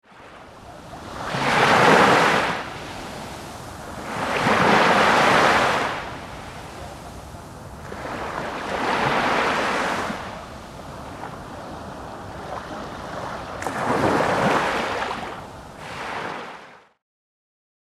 waves
waves.mp3